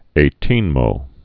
(ā-tēnmō)